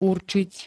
Zvukové nahrávky niektorých slov